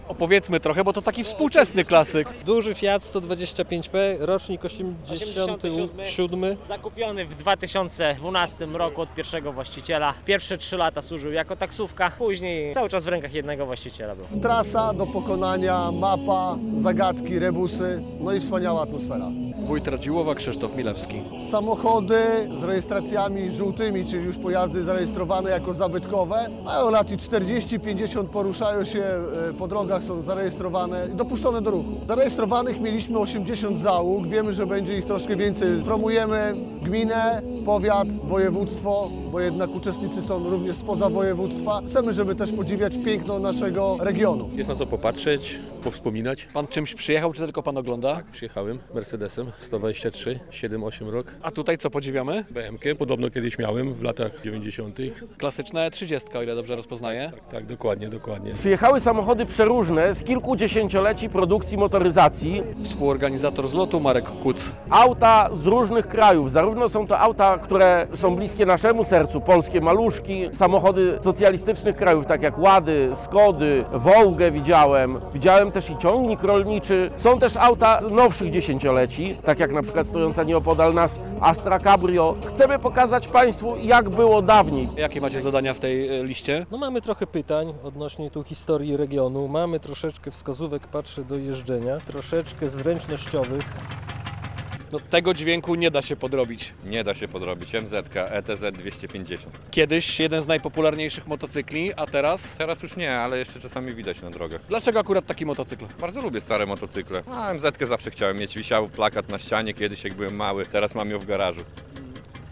Biebrzański Rajd Pojazdów Zabytkowych - relacja